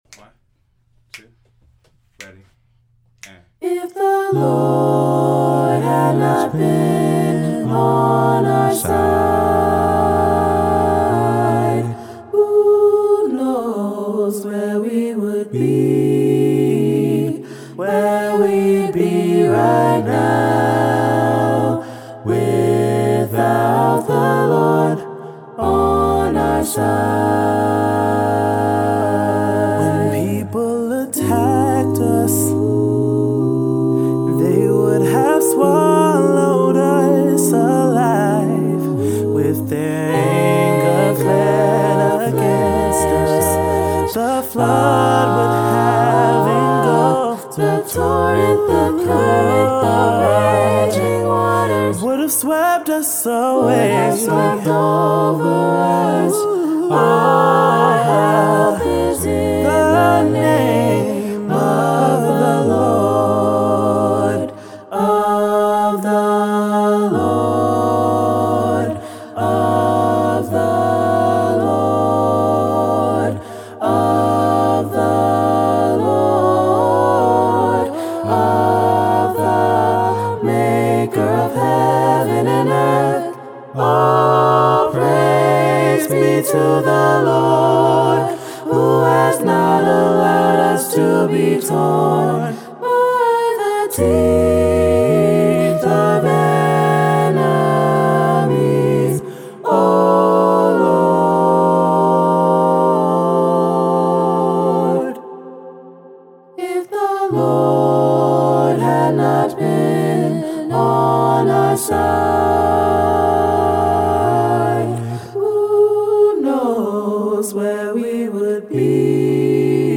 Voicing: SAATB; Tenor Solo